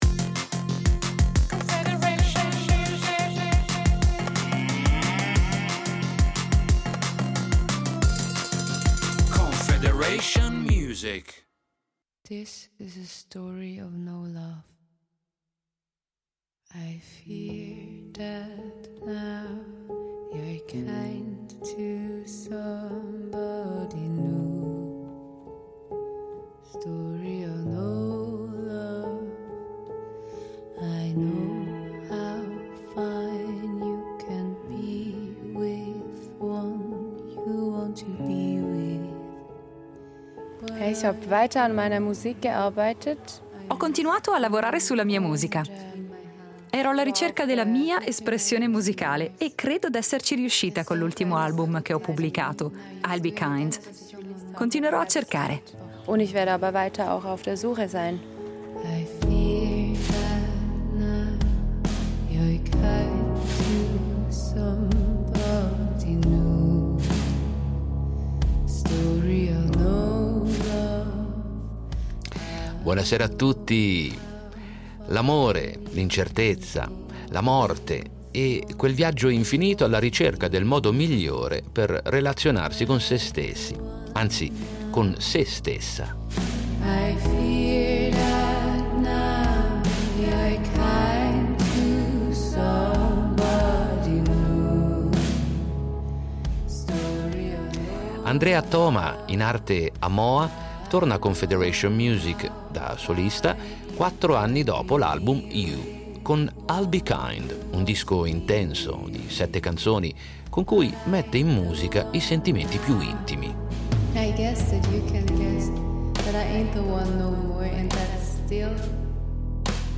Musica pop
L’elettronica dell’esordio ha lasciato spazio a un suono analogico e organico che rende tutto più vivido e rock, senza togliere un solo grammo alla delicatezza e alla sensibilità di una voce dalla grande estensione e che, su questo album, si può sentire spesso e volentieri in falsetto.